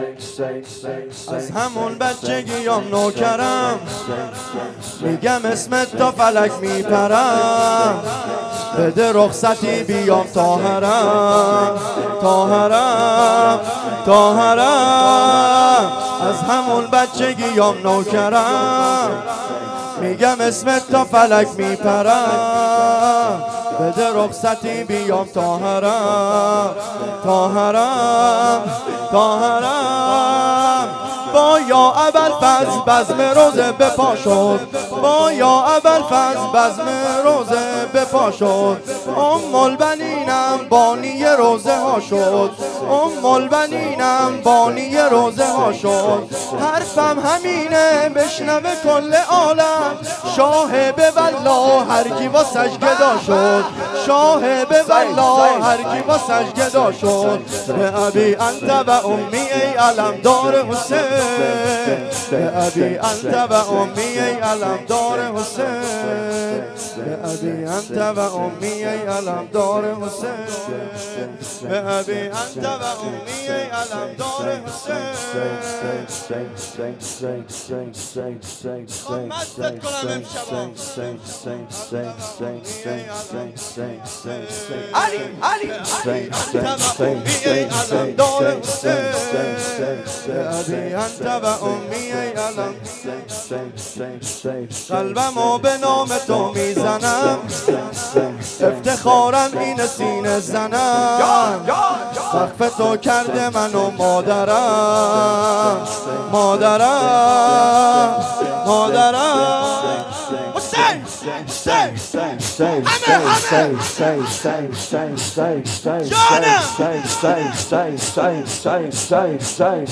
شور
مراسم هفتگی